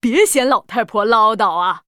文件 文件历史 文件用途 全域文件用途 Balena_tk_01.ogg （Ogg Vorbis声音文件，长度1.9秒，103 kbps，文件大小：24 KB） 源地址:游戏语音 文件历史 点击某个日期/时间查看对应时刻的文件。